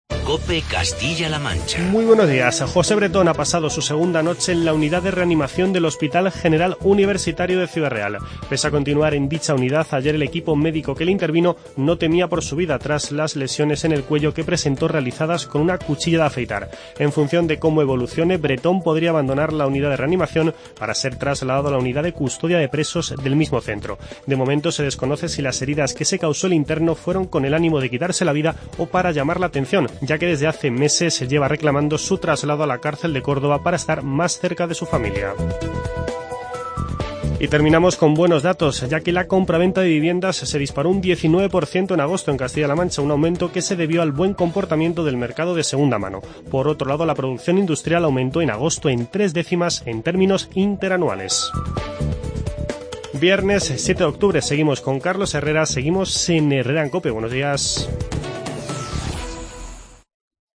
Informativo COPE Castilla-La Mancha